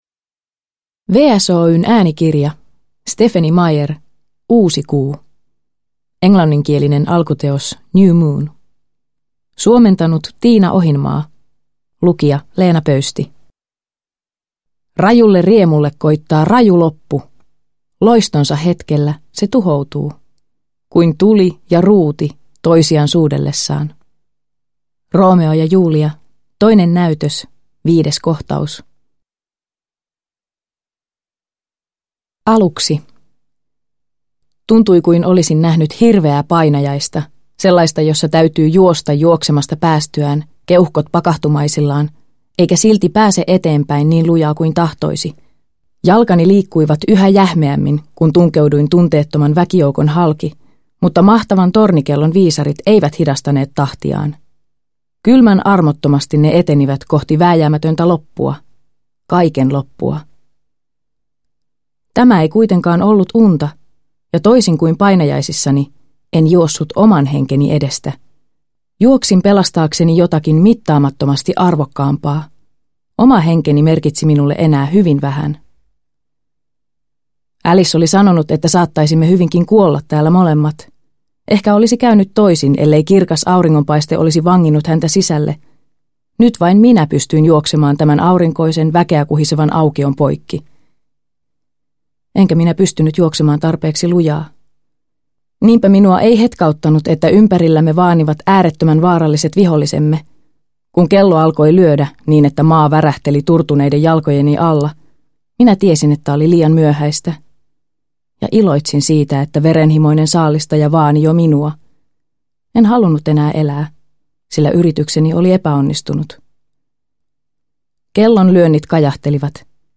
Uusikuu – Ljudbok – Laddas ner